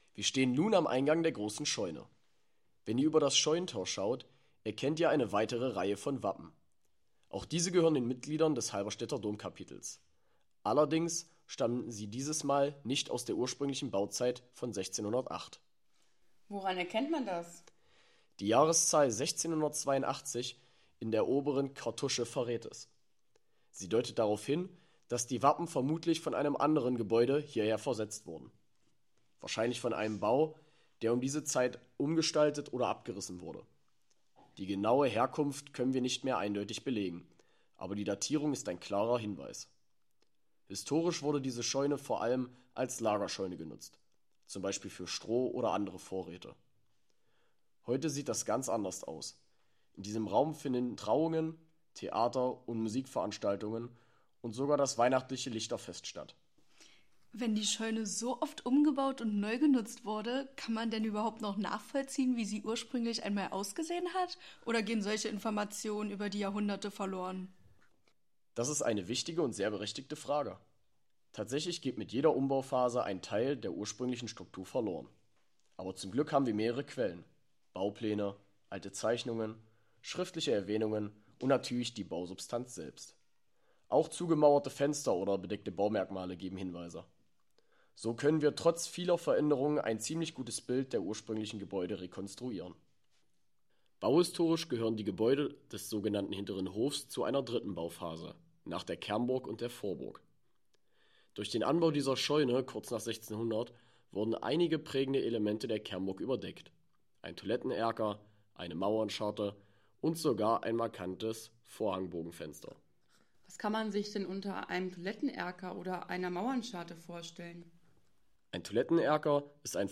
Diese Audiotour begleitet Sie durch Geschichte und Besonderheiten der Anlage.